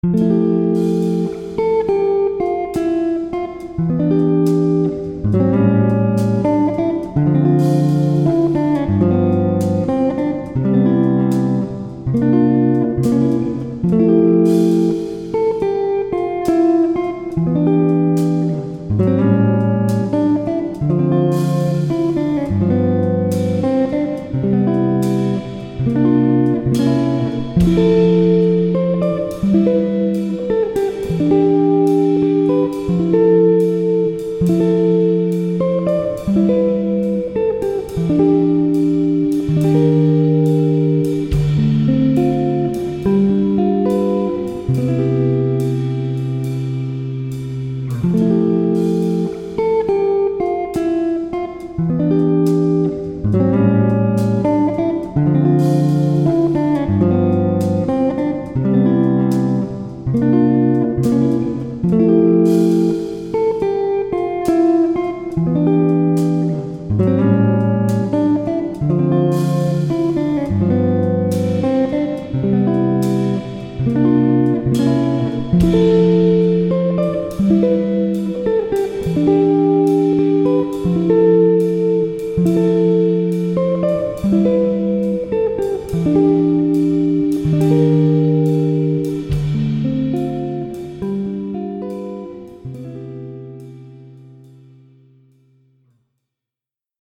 Home > Music > Ambient > Romantic > Relaxation > Sad